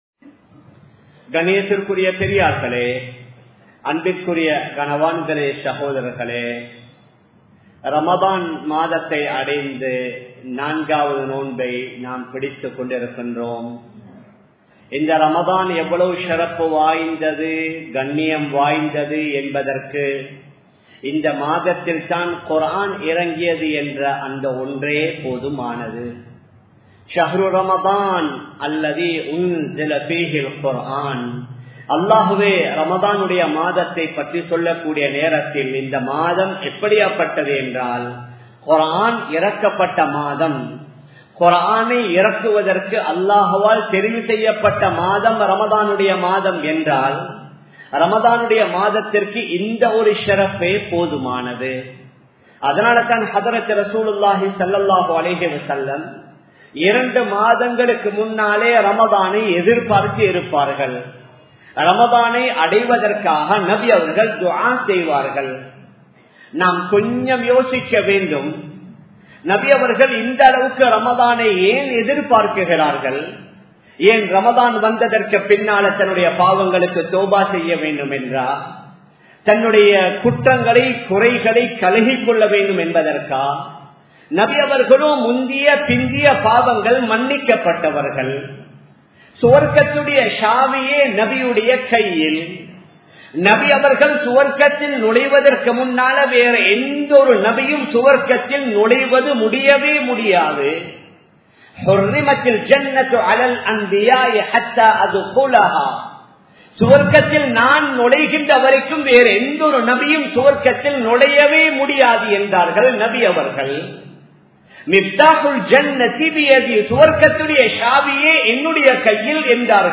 Ramalanum Al Quranum (ரமழானும் அல்குர்ஆனும்) | Audio Bayans | All Ceylon Muslim Youth Community | Addalaichenai
Colombo 11, Samman Kottu Jumua Masjith (Red Masjith)